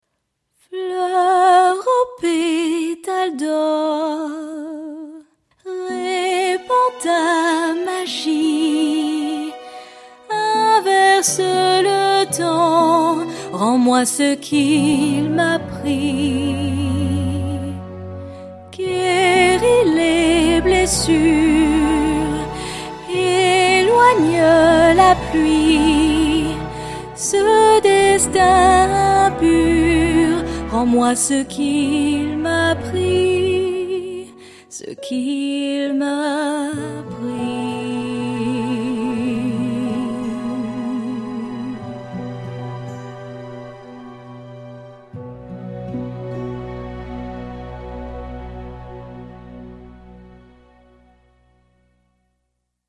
Démo Chant
Comédienne, chanteuse franco-américaine